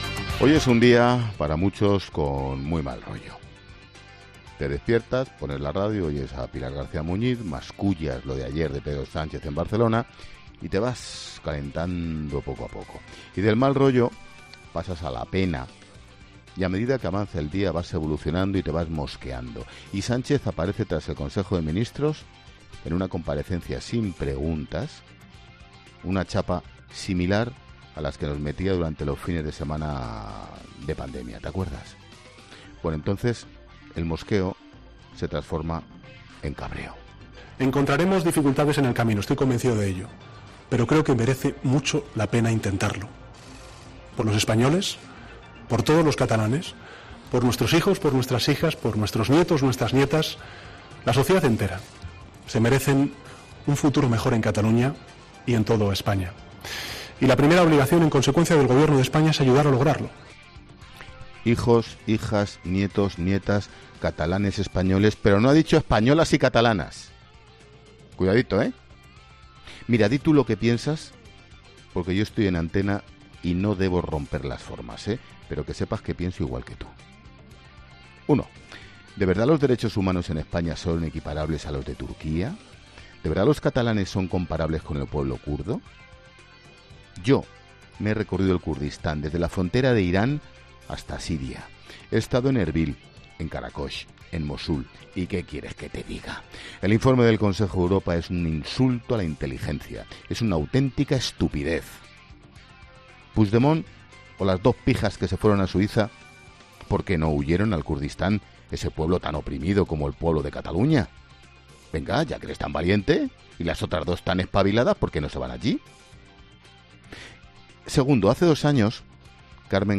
Monólogo de Expósito
El director de 'La Linterna', Ángel Expósito, reflexiona en su monólogo sobre los indultos con sus respectivas claves y enfoques